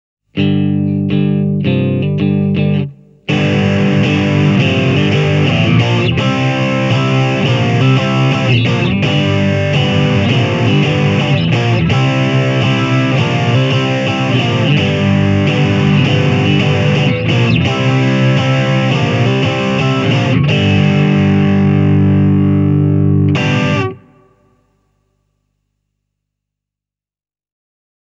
Jo Distortion-säätimen alkumetreissä on hyvin selvää, että DS-1X-pedaali tarjoaa enemmän säröä kuin sen Overdrive-veli (Vox Mark III, Dist klo 9):